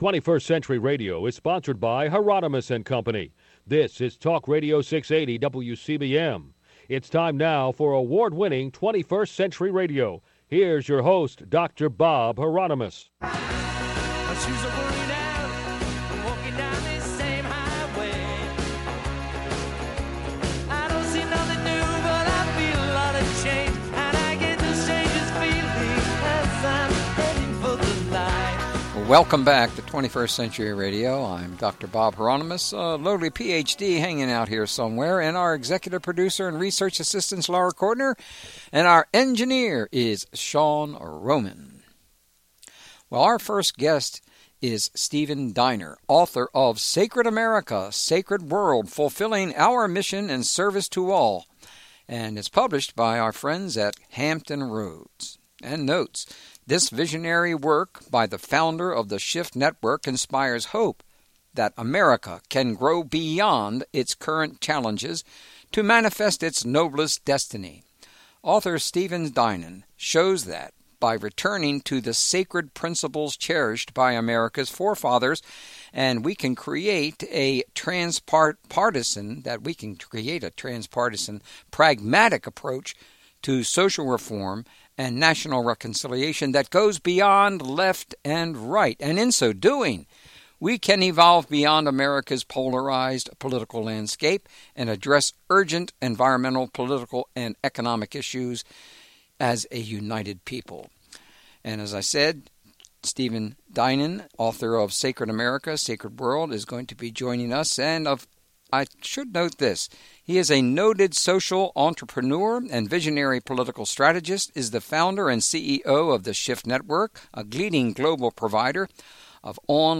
Interview with 21st Century Radio – Sacred America, Sacred World